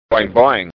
I got Cory to say "Boing Boing" into my Olympus voice recorder.
There was a LOT of noise in there.
Here I've been running around saying /BOING-boing/ for lo these many years, when in fact it's /boing-BOING/, according to the emphasis on Cory's MP3 soundbite here.
Actually, I was deliberately mispronouncing it, the way that Brits seem to -- I always go equal-emphasis.